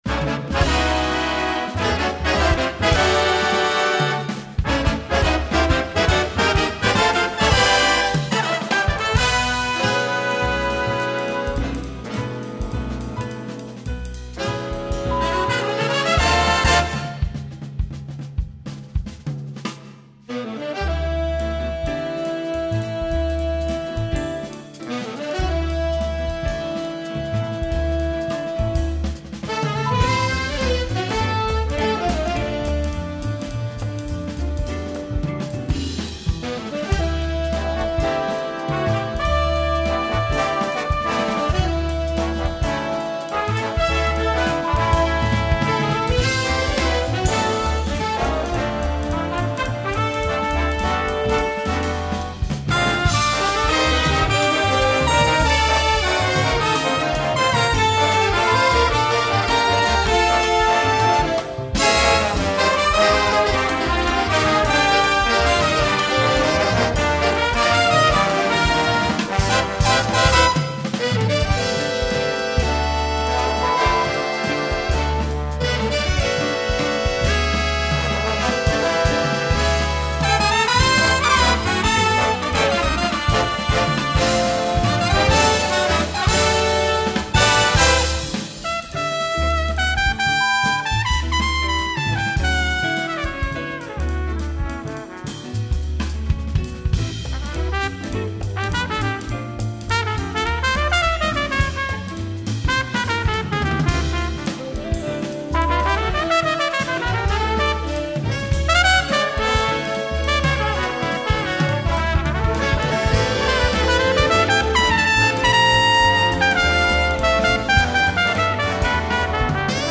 Répertoire pour Jazz band - Jazz Ensemble